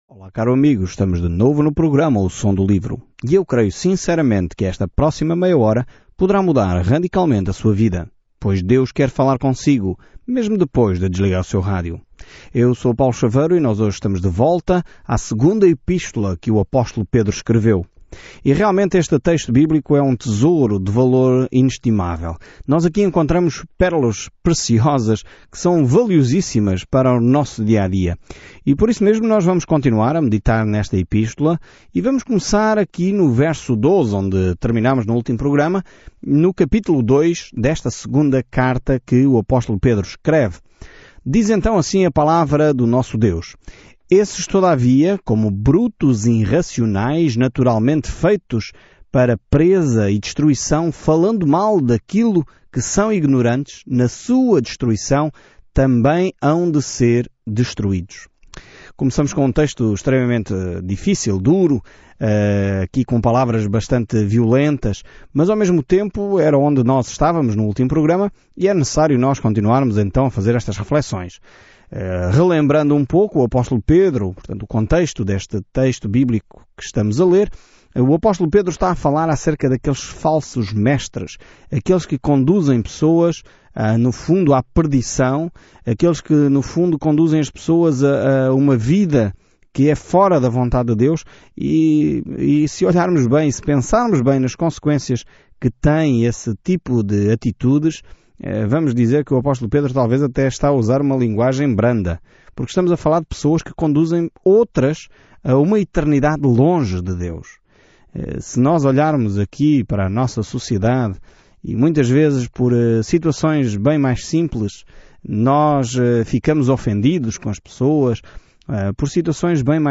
Escritura 2 PEDRO 2:12-17 Dia 10 Iniciar este Plano Dia 12 Sobre este plano A segunda carta de Pedro é toda sobre a graça de Deus – como ela nos salvou, como nos mantém e como podemos viver nela – apesar do que os falsos mestres dizem. Viaje diariamente por 2 Pedro enquanto ouve o estudo em áudio e lê versículos selecionados da palavra de Deus.